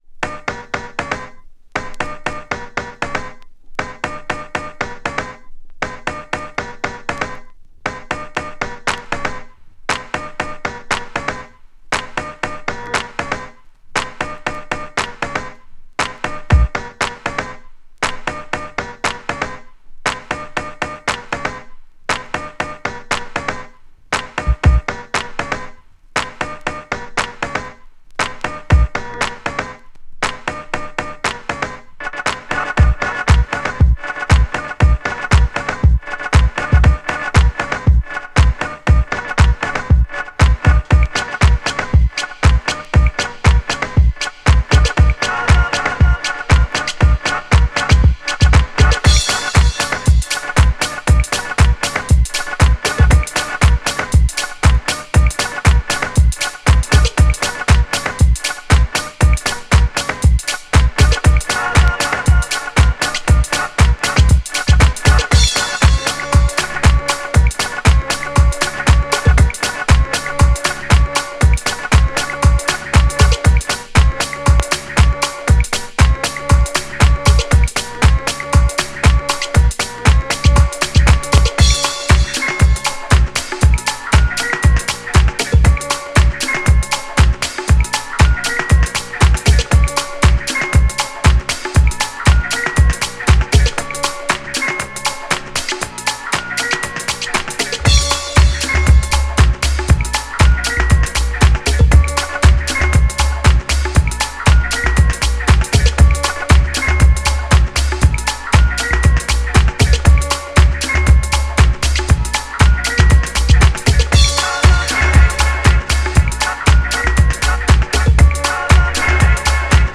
GENRE House
BPM 116〜120BPM